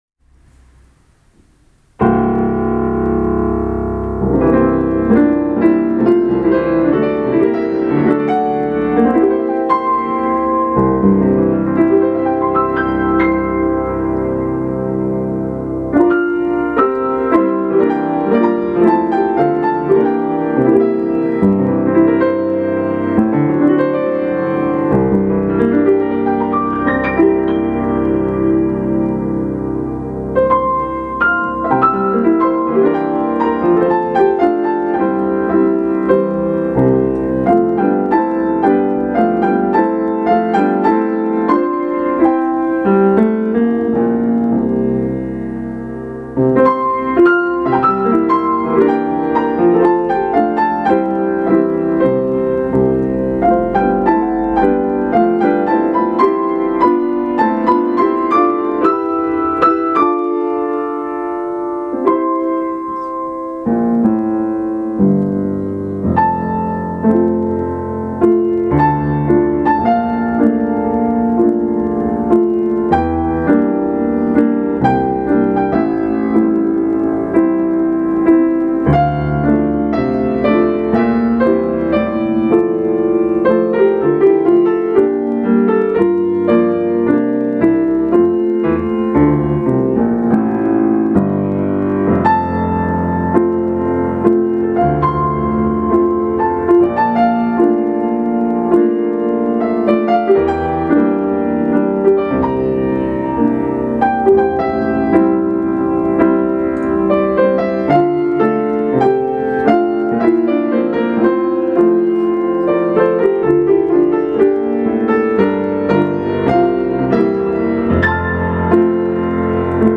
piano
今回は、「つばめ」のオペラの中から一番有名なアリア、〝ドレッタの夢の歌〟をピアノで演奏しました。
録音は色々と試行錯誤しましたが、結局解決しないので諦めました(^_^;)